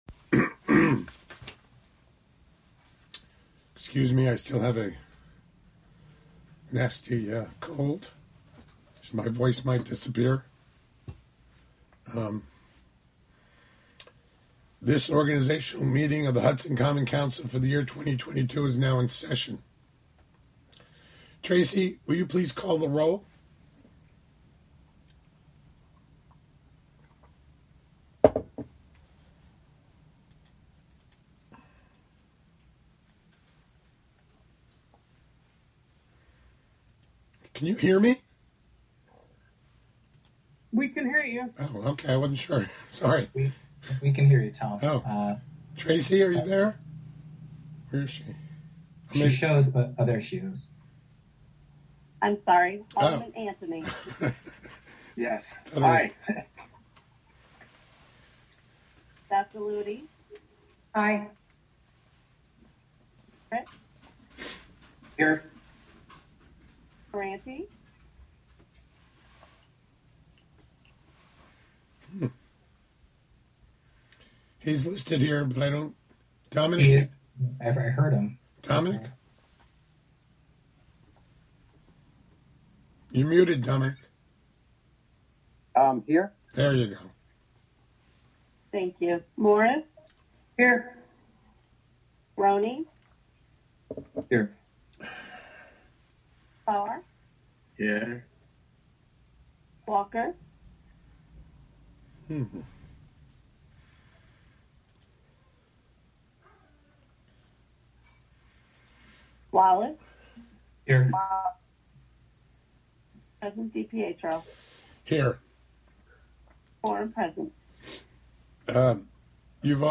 Live from the City of Hudson: Hudson Common Council (Audio)